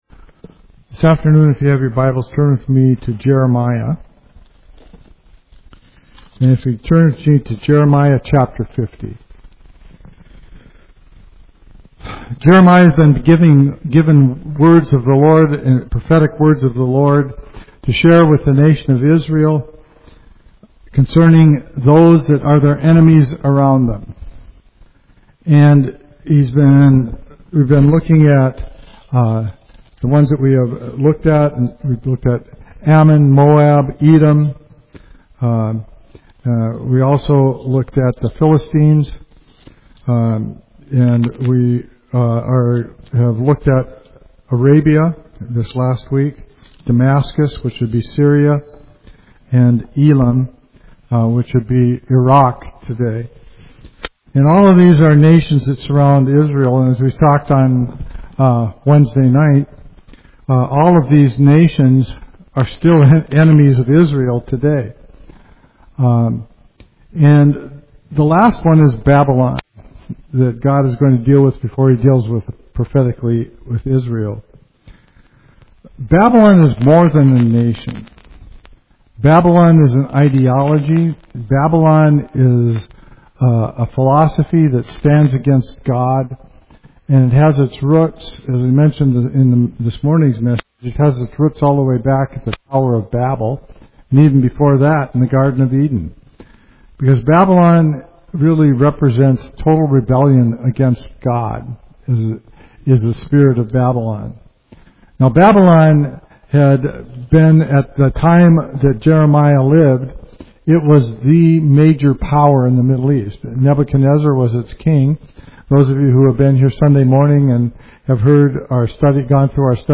Study Jeremiah 50:1–10: God declares judgment on Babylon and promises deliverance for His people. A message of justice, truth, and hope today.